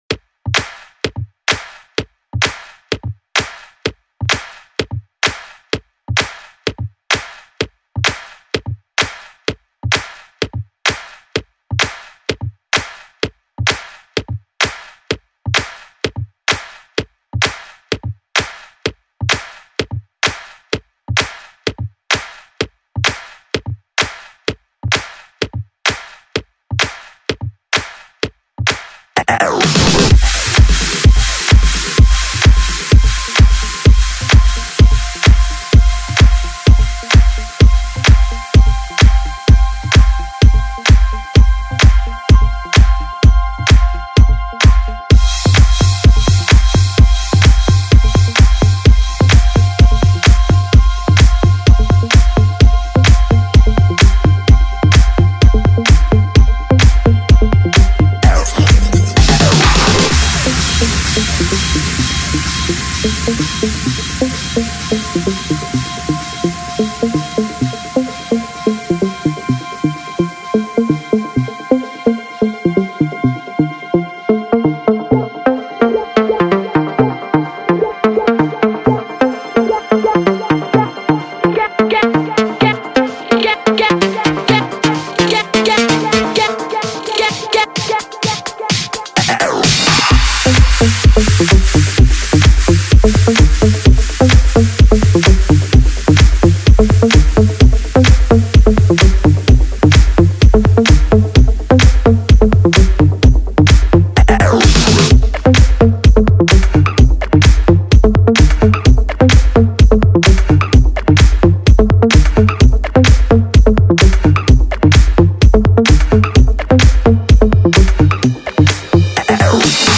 Disco, House, Dance, Breakbeat